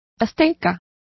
Complete with pronunciation of the translation of aztec.